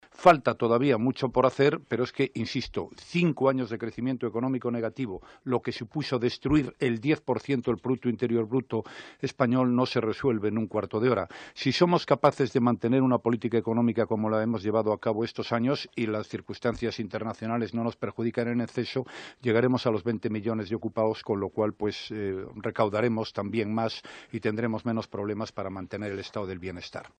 En una entrevista en Onda Cero se ha mostrado convencido de que es posible seguir creando entre 400.000 y 500.000 puestos de trabajo cada año tras el duro golpe al empleo provocado por la crisis.